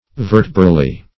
Meaning of vertebrally. vertebrally synonyms, pronunciation, spelling and more from Free Dictionary.
Search Result for " vertebrally" : The Collaborative International Dictionary of English v.0.48: Vertebrally \Ver"te*bral*ly\, adv.